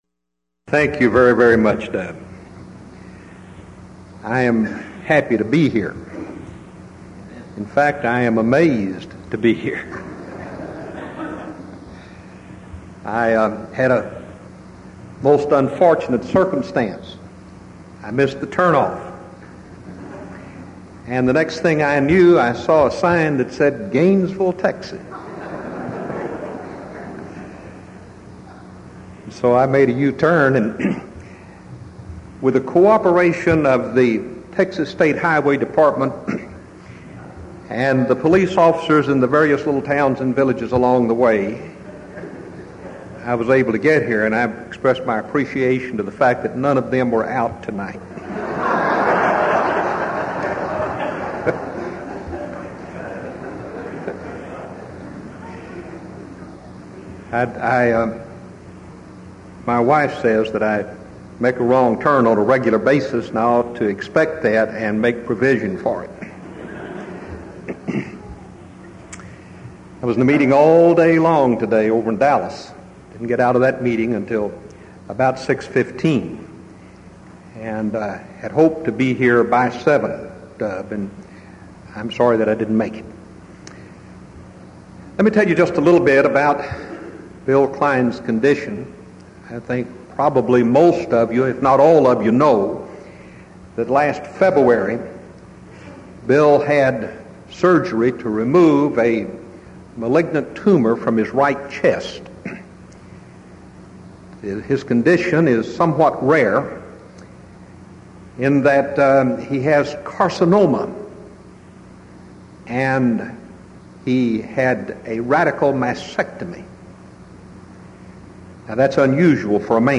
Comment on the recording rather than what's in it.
Event: 1989 Denton Lectures